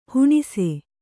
♪ huṇise